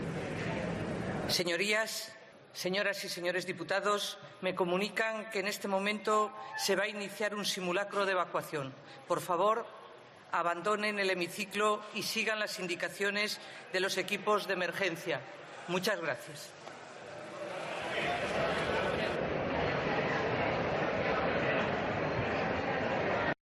Ana Pastor anuncia que hay que desalojar el hemiciclo
A las once menos cuarto de la mañana han sonado las alarmas de los dispositivos de emergencia de la Cámara Baja, cuya presidenta, Ana Pastor, ha pedido a los diputados que abandonaran el edificio.